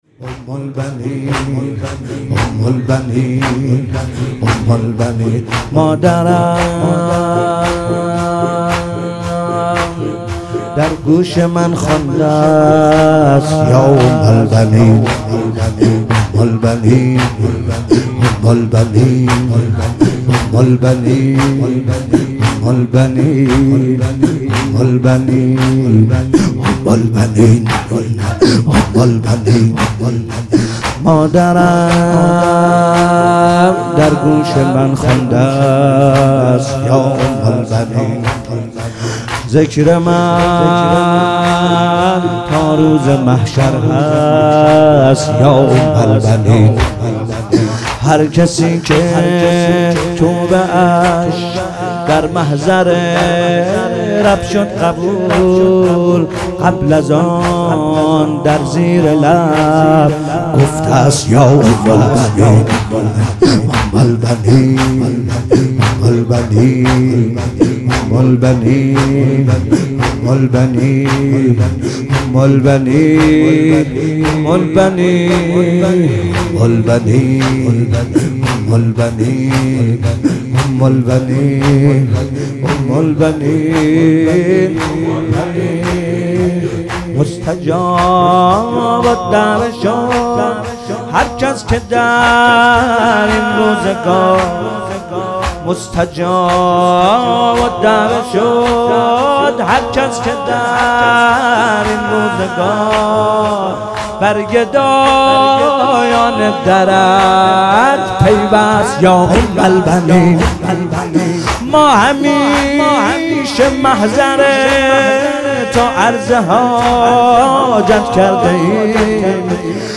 هفتگی 15 بهمن 98 - پیش زمینه - مادرم‌درگوش‌من‌خواندست‌یاام‌البنین